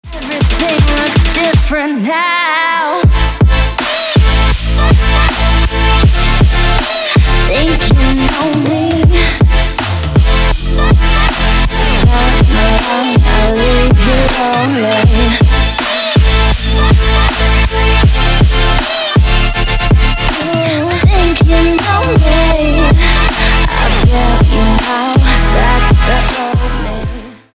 • Качество: 256, Stereo
dance
EDM
Trap
club